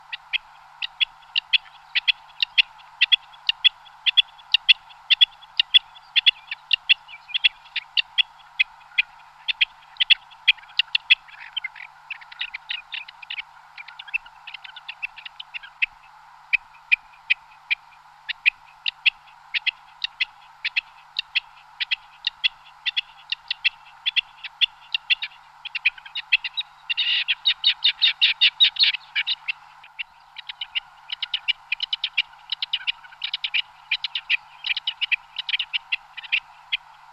Кеклики манок для куропатки